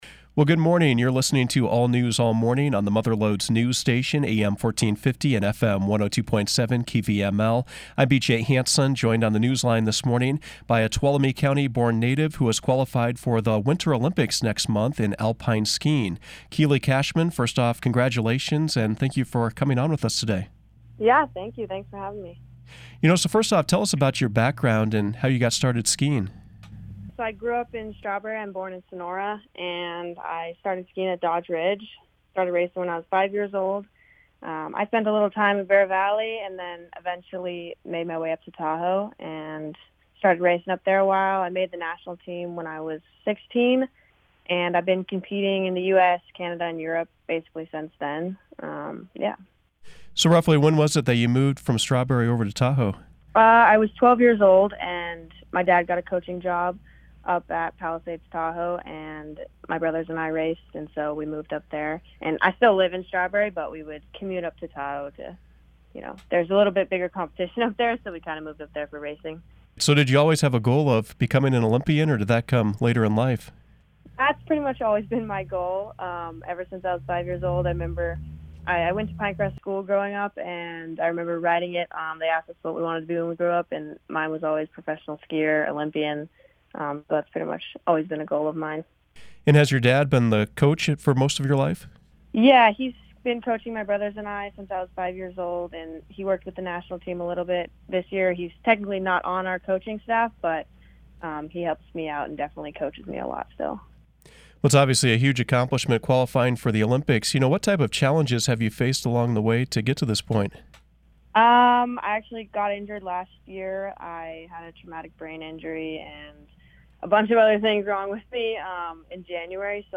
Clarke Broadcasting caught up with her via telephone, and she is featured as today’s KVML “Newsmaker of the Day.”
Keely Cashman Newsmaker Interview